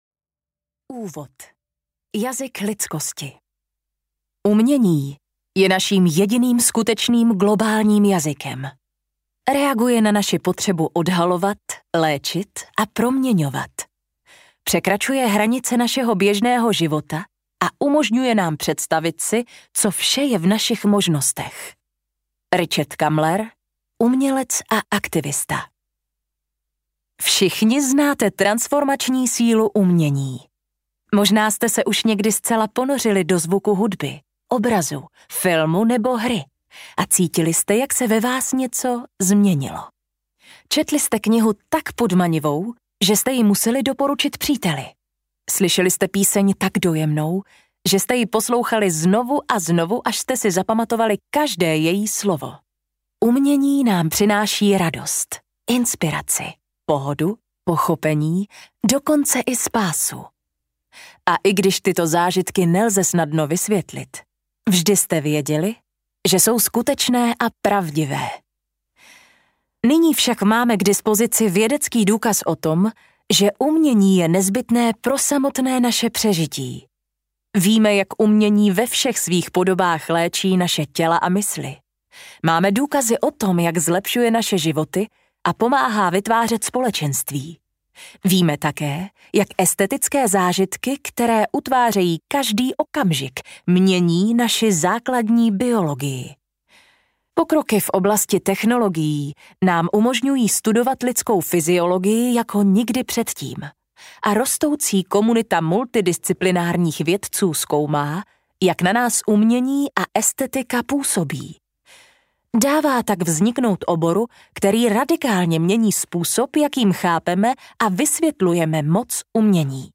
Mozek pod vlivem umění audiokniha
Ukázka z knihy